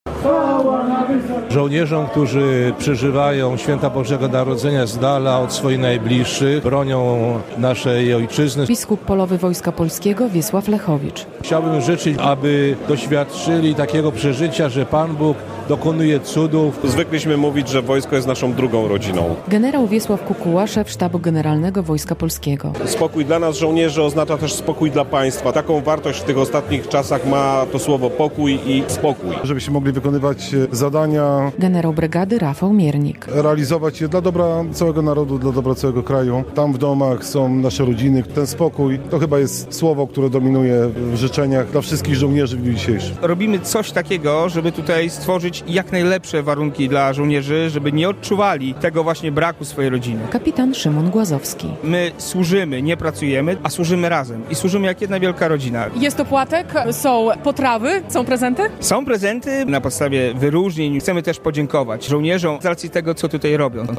Na poligonie w Zielonej koło Białegostoku odbyło się w niedzielę (24.12) wigilijne spotkanie Wojskowego Zgrupowania Zadaniowego "Podlasie".
Można było usłyszeć kolędy, ale przede wszystkim życzenia o pokój i spokój.